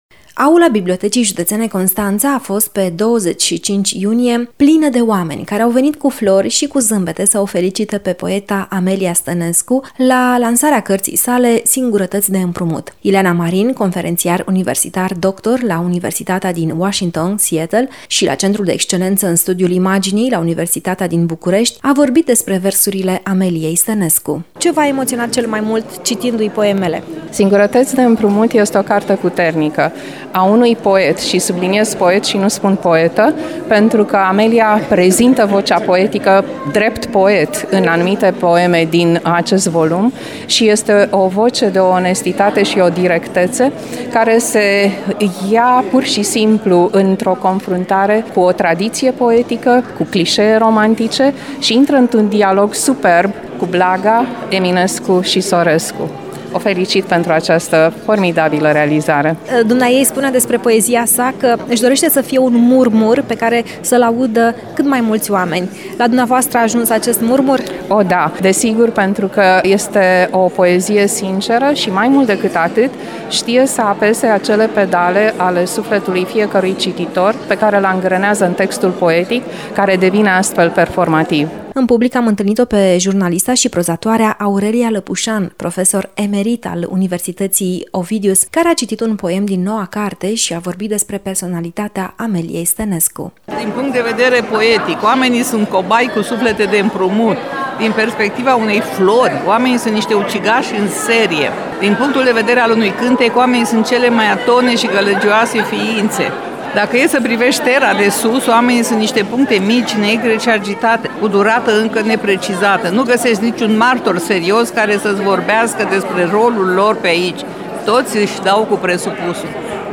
Evenimentul a avut loc la Biblioteca Județeană Constanța.
În parfum de mare și de fericire, în acorduri de pian și de aplauze, murmurul poeziilor din volumul “Singurătăți de împrumut” a ajuns la inima cititorilor.